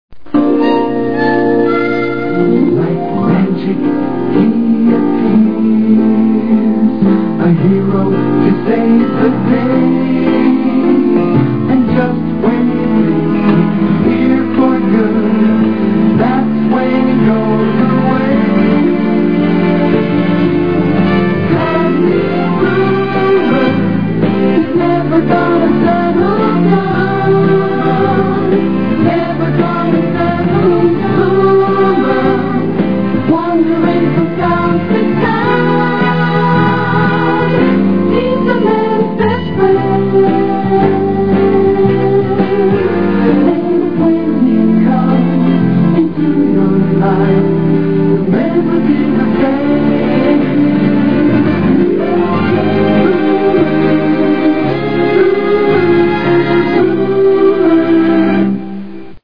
Easy Listening theme              :58